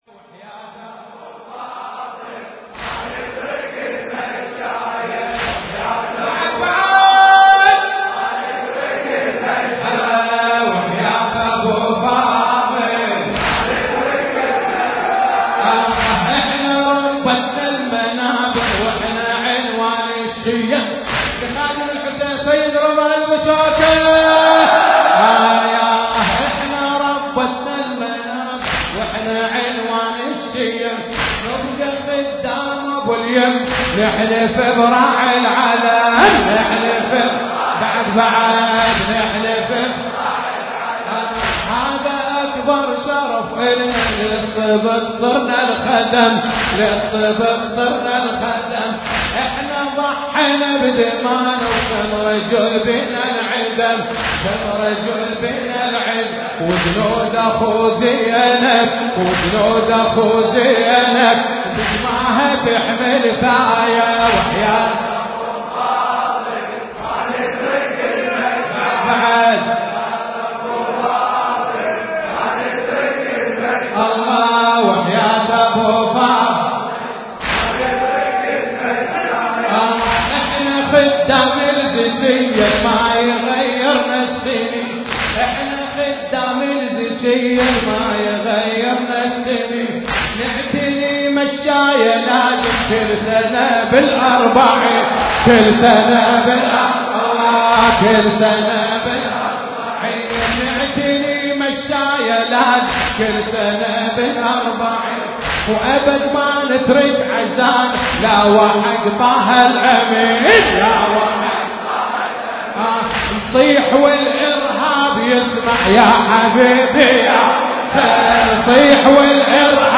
المكان: حسينية داود العاشور- البصرة